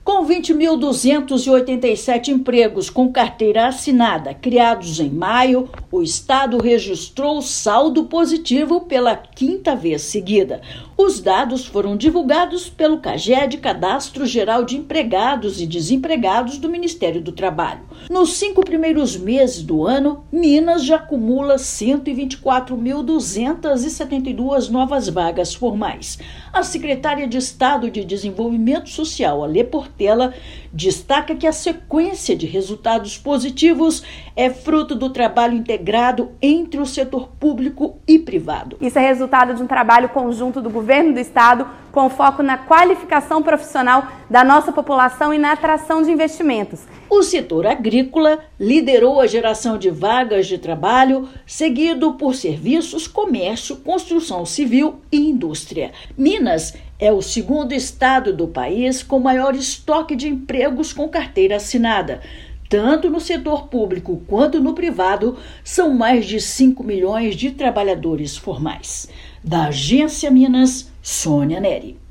Estado registrou, em maio, saldo positivo na geração de postos formais de trabalho pela quinta vez consecutiva. Ouça matéria de rádio.